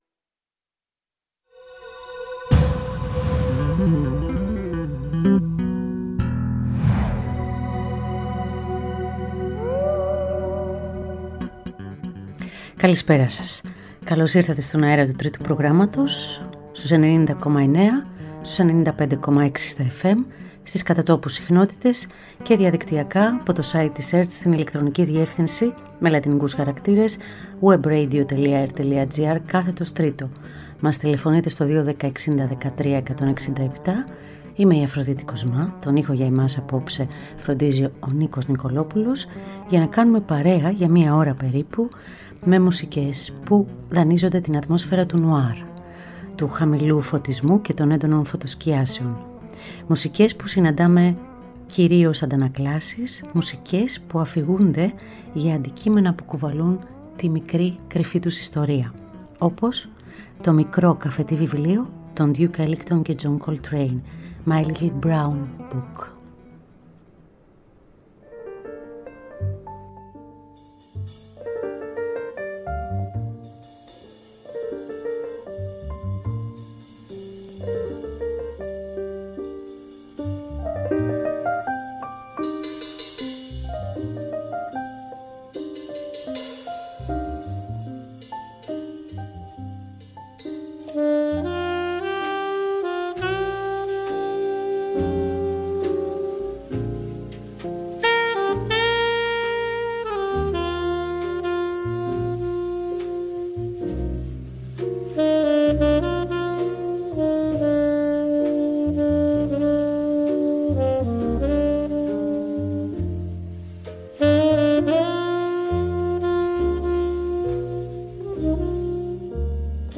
Απόψε θα δανειστούμε την ατμόσφαιρα του νουάρ